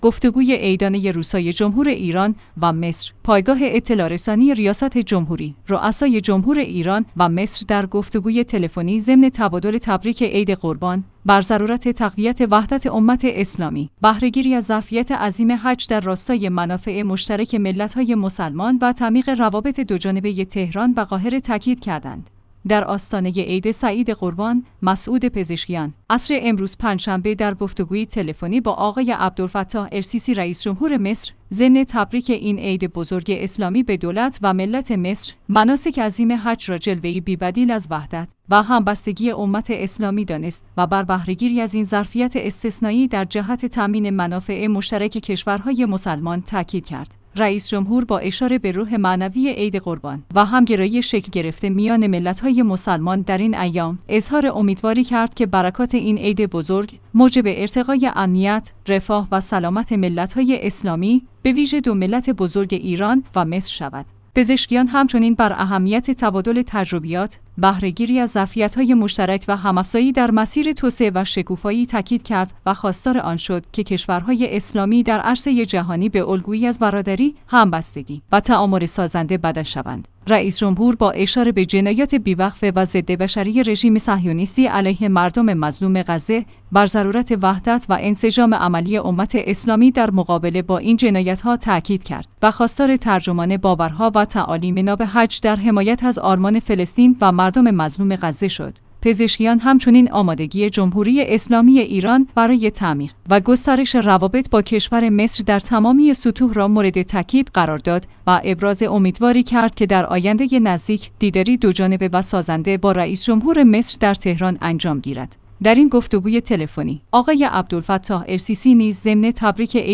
گفت‌وگوی عیدانه روسای‌جمهور ایران و مصر